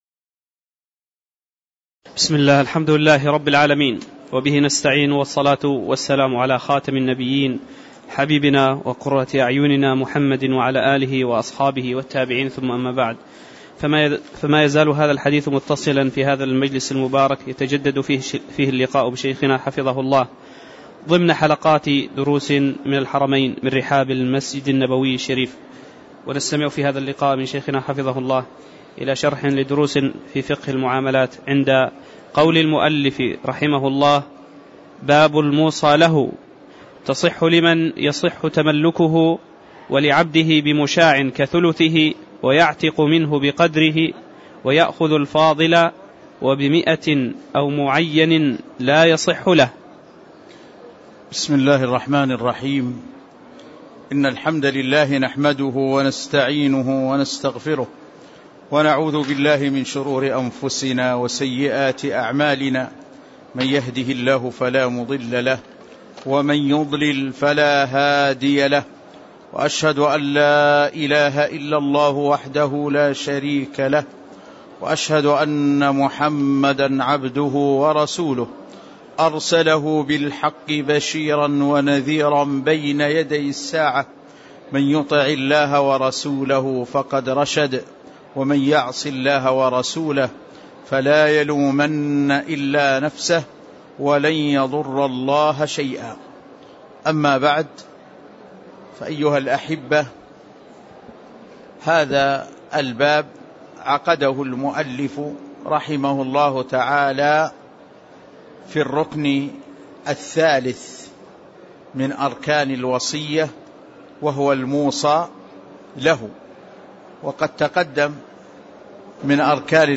تاريخ النشر ١٦ ربيع الثاني ١٤٣٧ هـ المكان: المسجد النبوي الشيخ